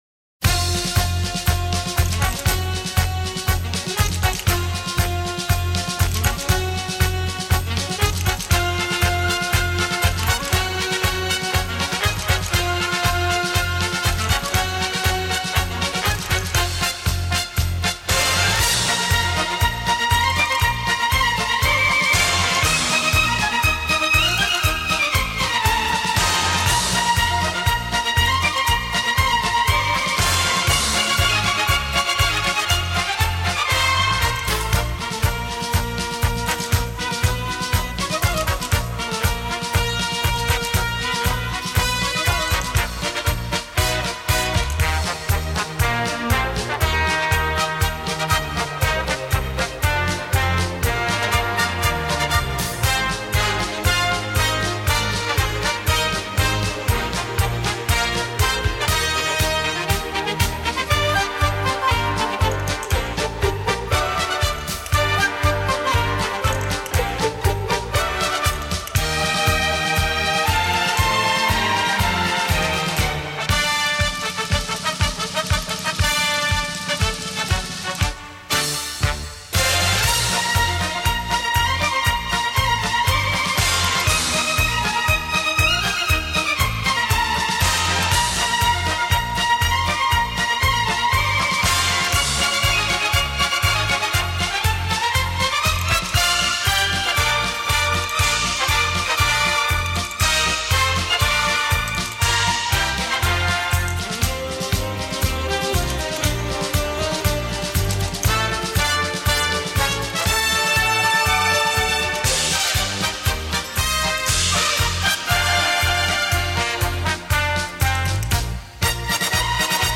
Genre:Dance